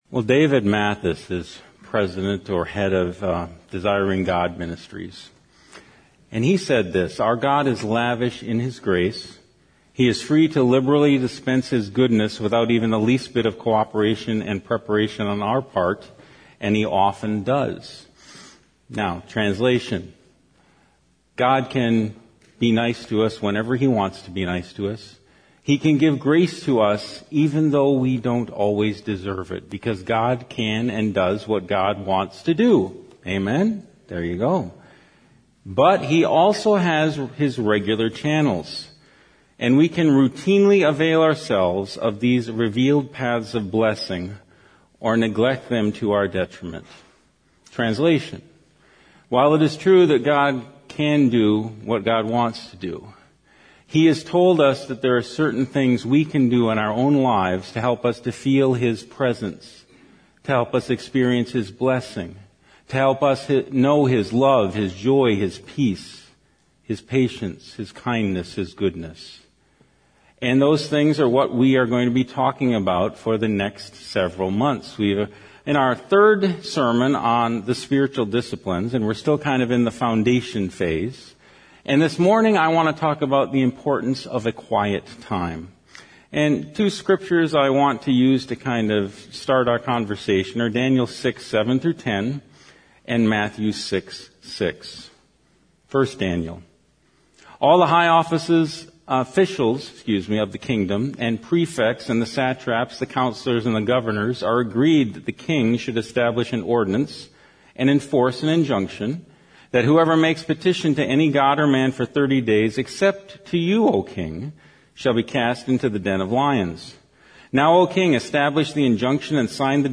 First Baptist Church Sermons